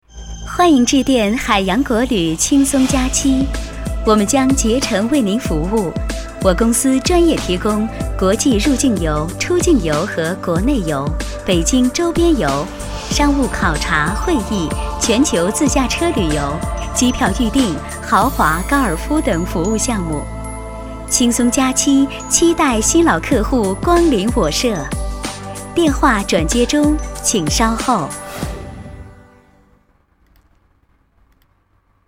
舒缓 自然 亲切